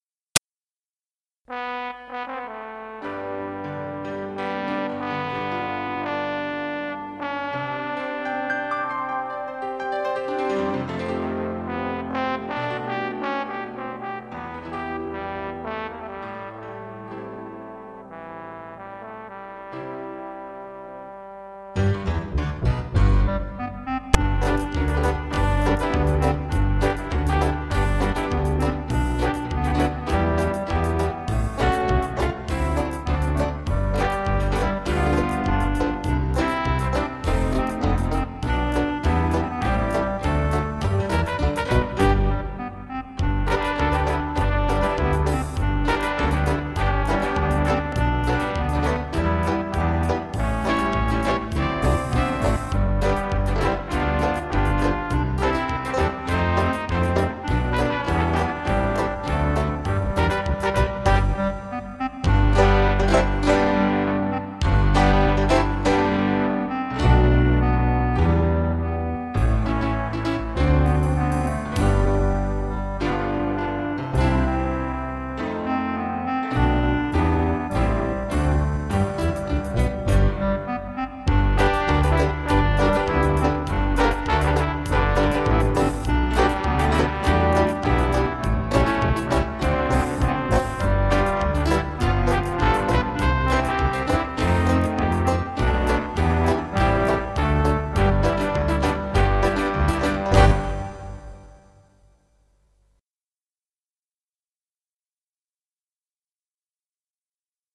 Oh when the saints. Karaoke flauta